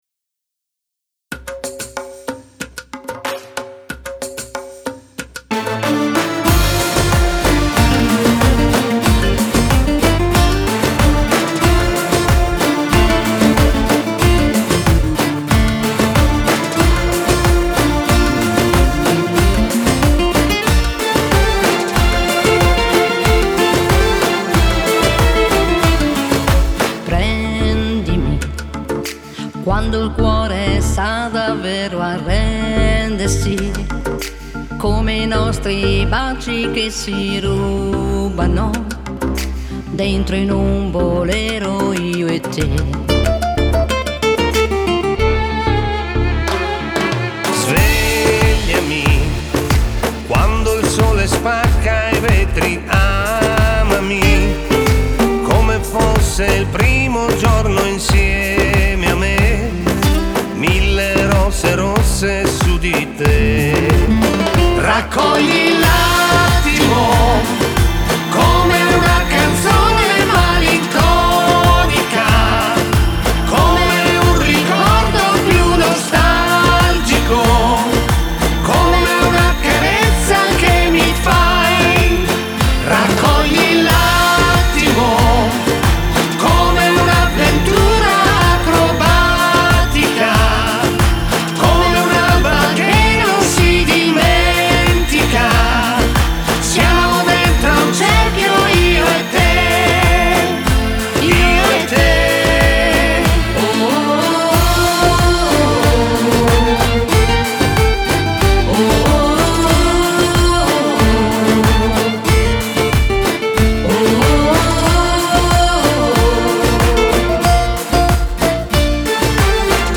play per DJ Ballo di gruppo